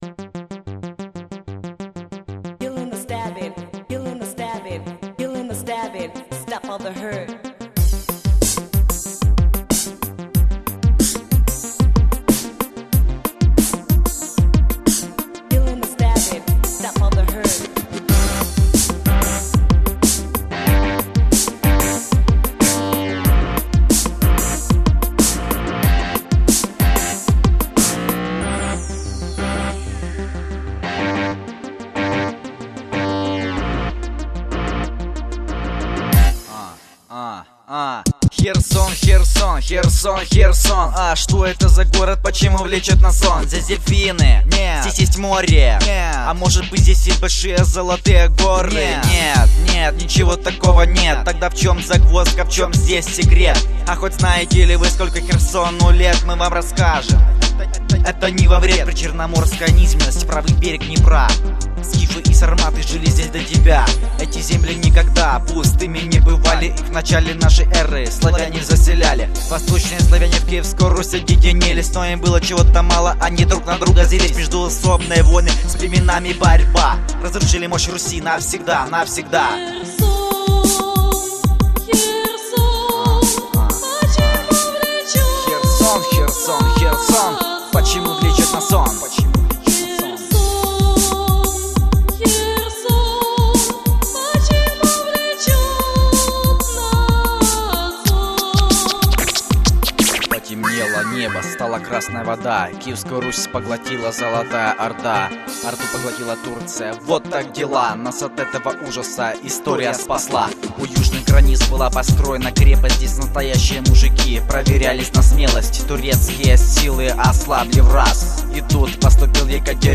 Похоже на рэп.